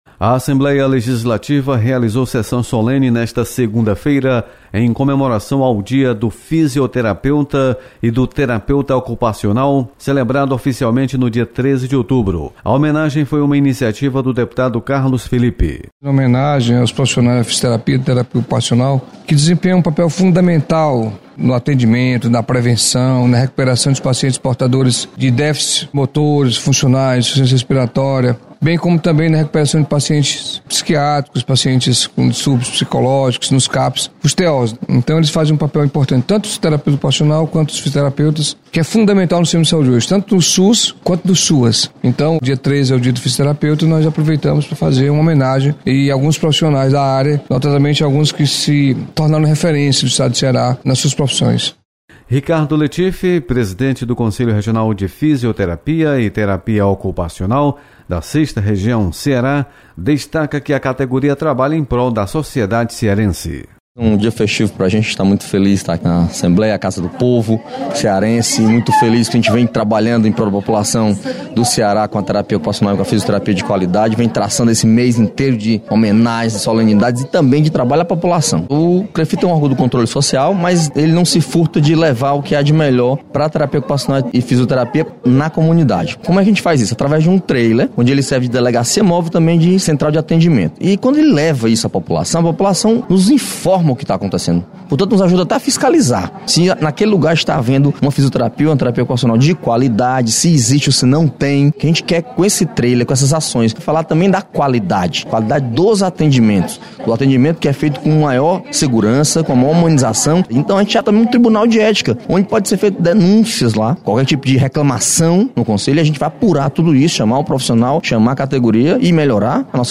Solenidade